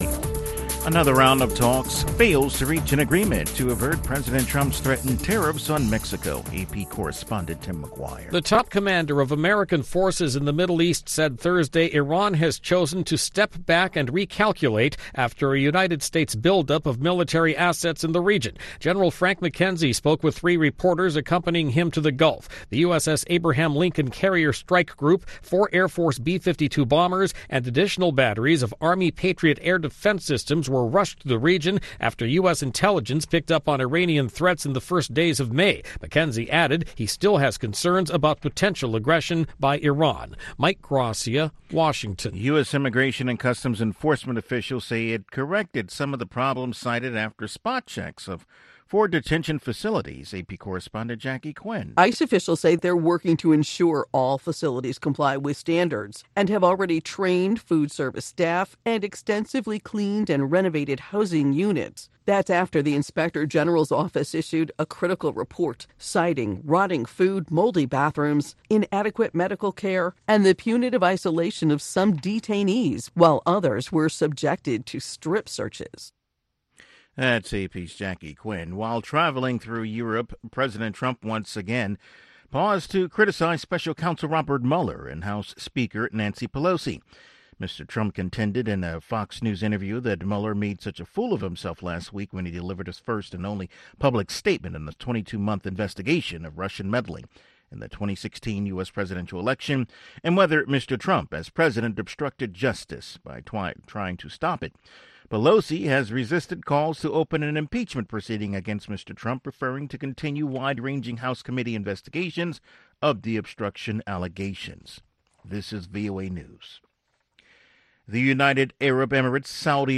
contemporary African music and conversation